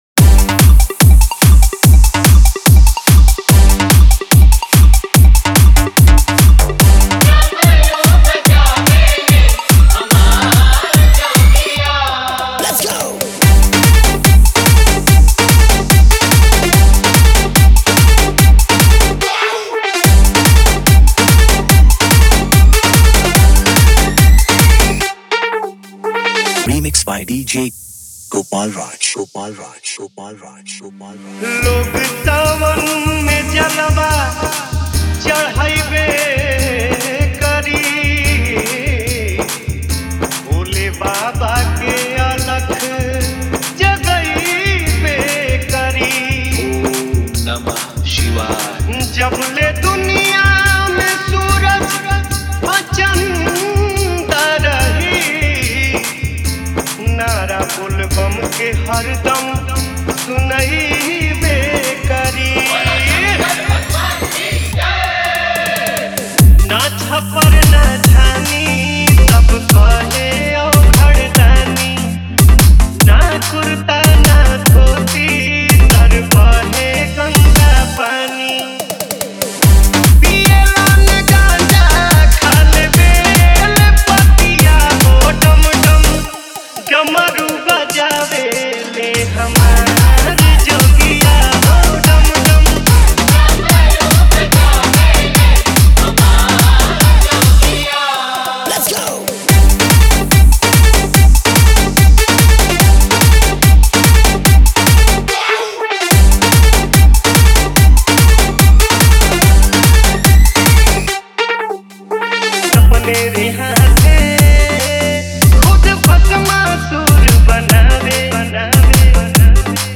Category : Bhakti DJ Remix Songs